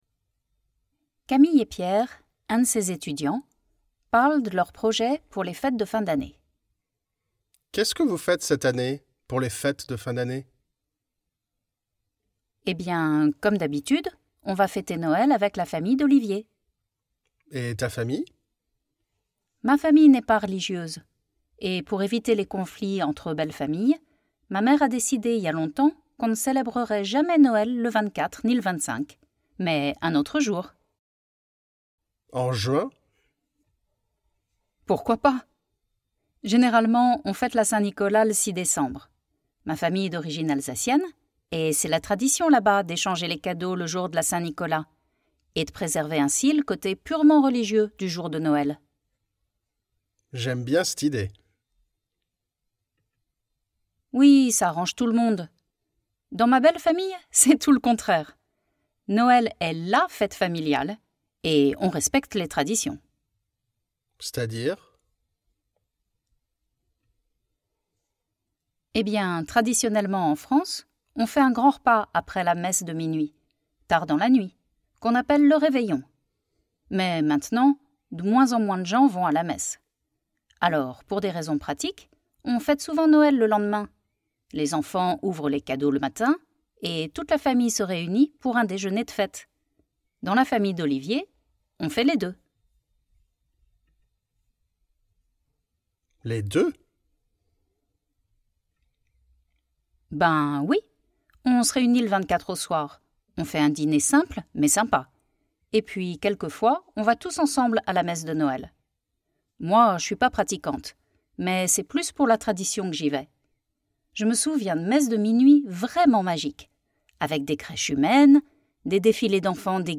Authentic French Christmas Conversation
Modern Spoken French
dialogue_noel_2026_modern.mp3